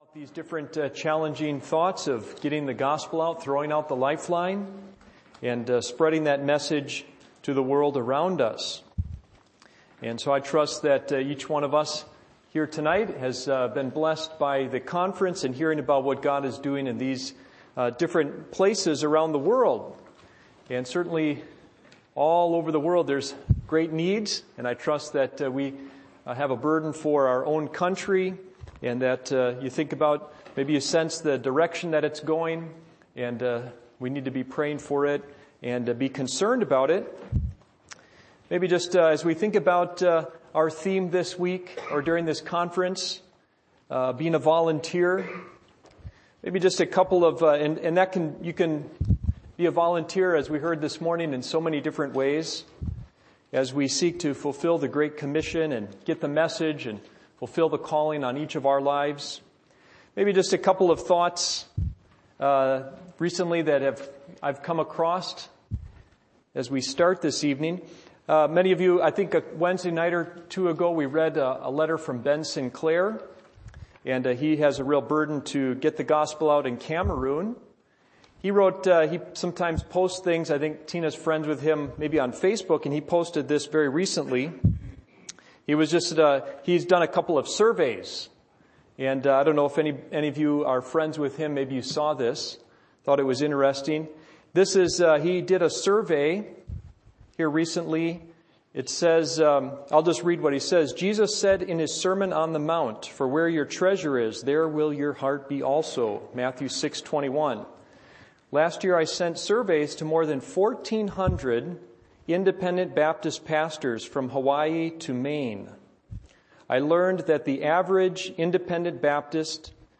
Missions Conference 2019 Service Type: Sunday Evening %todo_render% « Here Am I